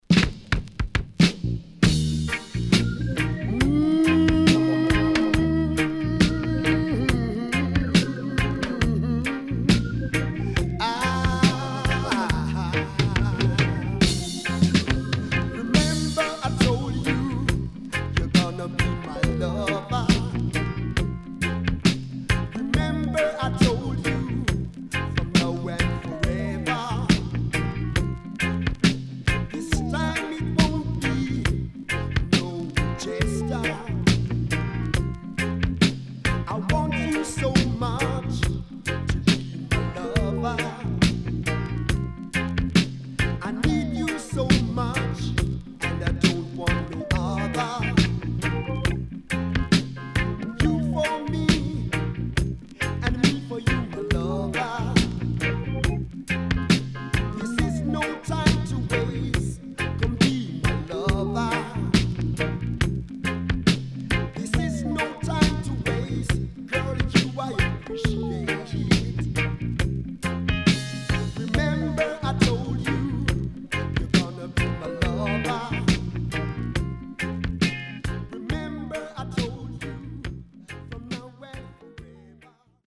HOME > DISCO45 [VINTAGE]  >  KILLER & DEEP
SIDE A:少しチリノイズ入りますが良好です。